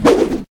fire3.ogg